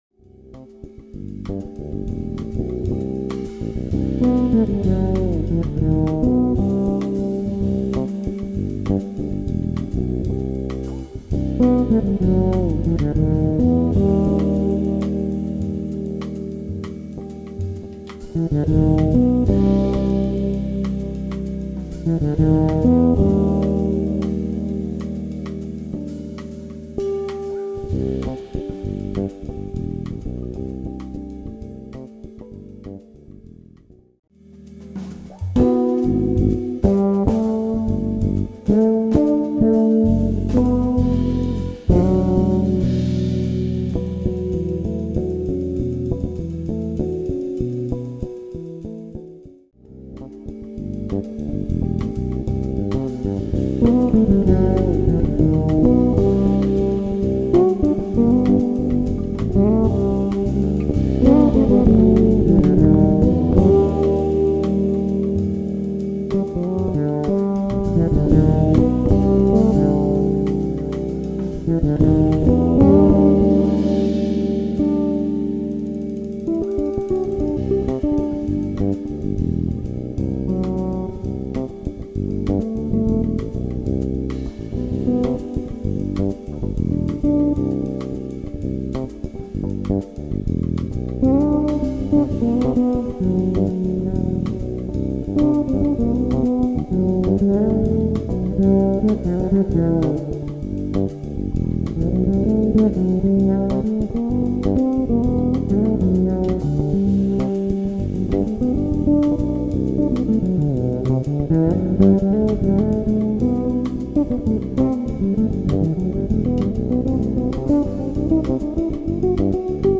Ibanez SR 500 fretted full HGT conversion